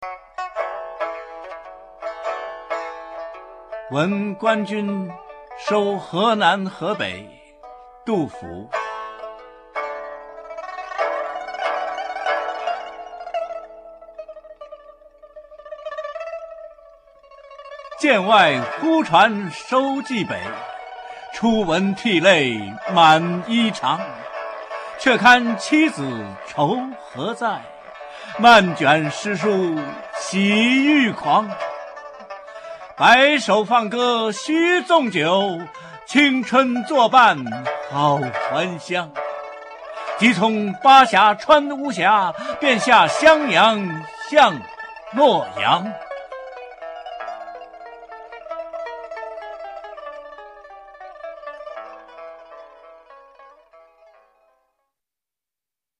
《闻官军收河南河北》原文、翻译与赏析（含朗读）